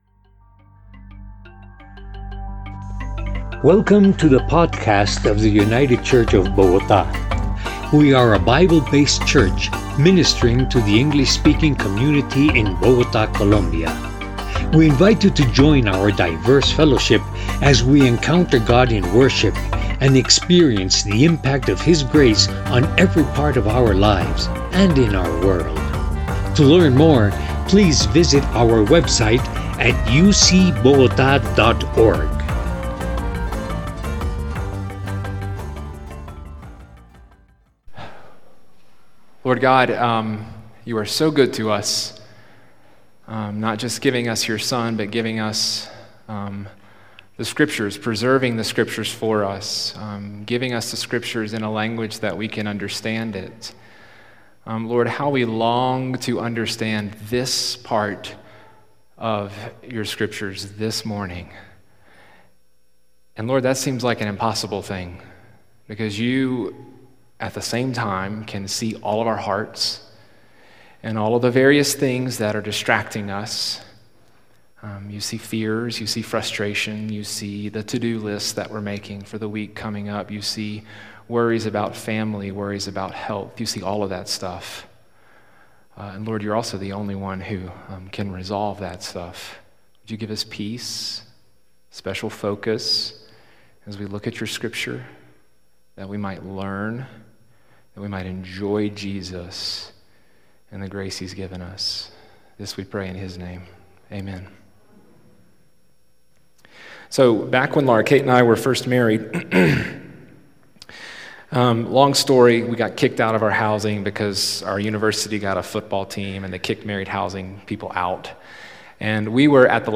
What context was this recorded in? The Reign of Grace – United Church of Bogotá